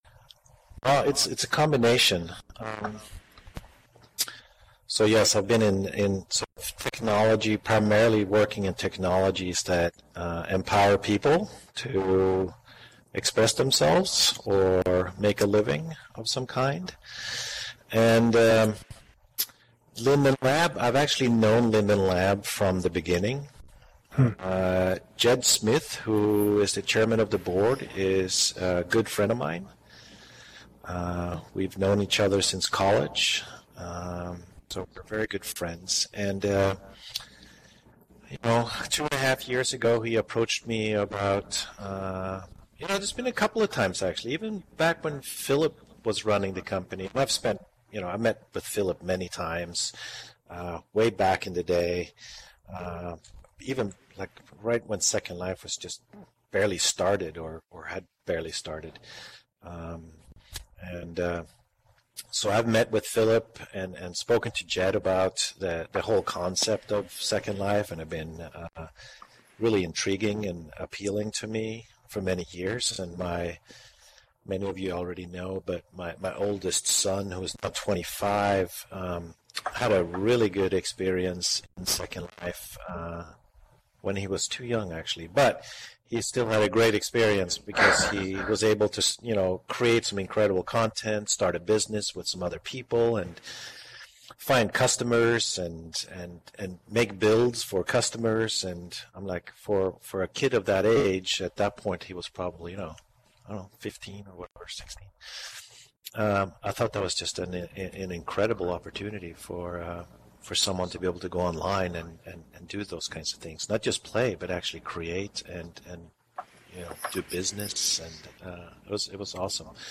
answering questions from hosts